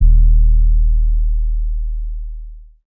YM Sub 8.wav